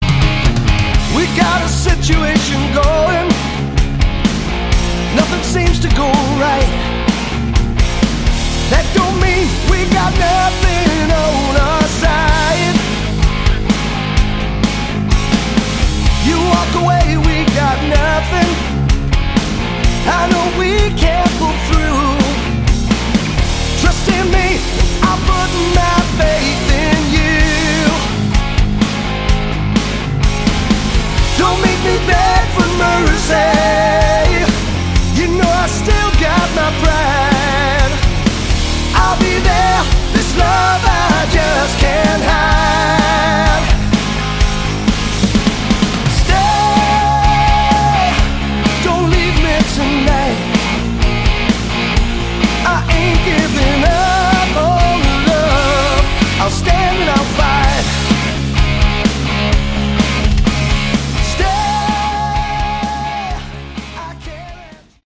Category: Hard Rock
lead and backing vocals, bass, guitars
electric, acoustic and slide guitars
drums
kayboards, backing vocals